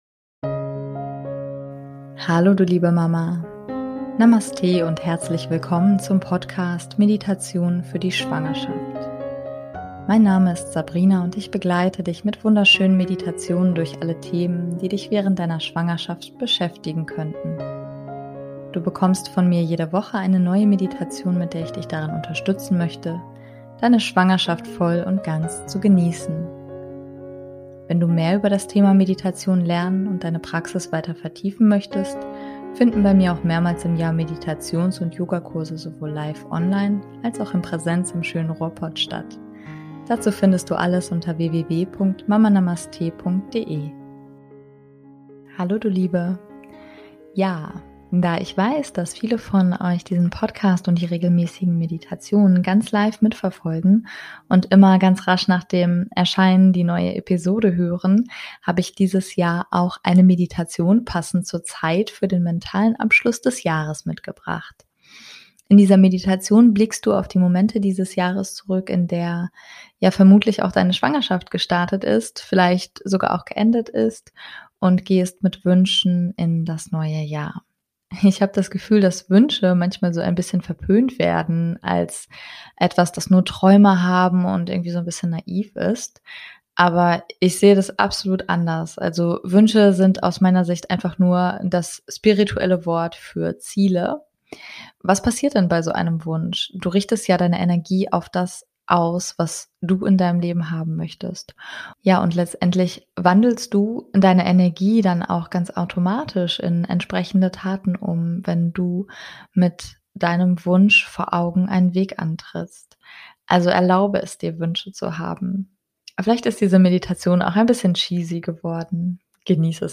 In diesem Jahr habe ich eine Meditation passend zur Zeit für den mentalen Abschluss des Jahres mitgebracht.